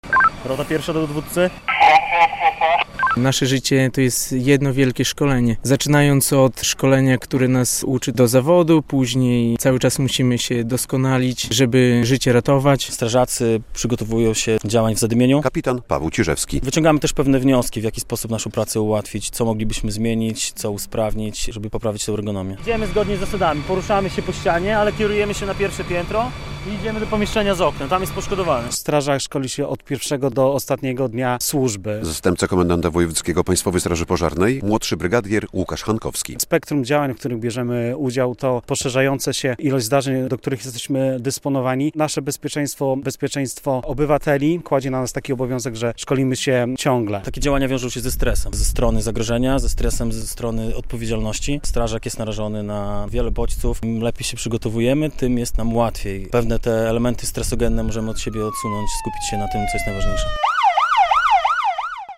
Podlascy strażacy wciąż doskonalą swoje umiejętności - relacja